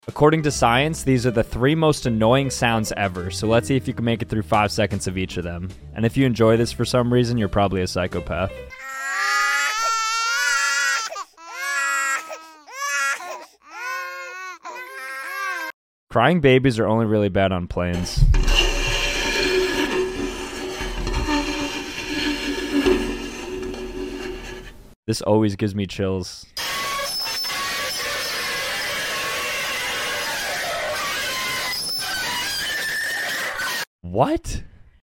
The most annoying sounds of all time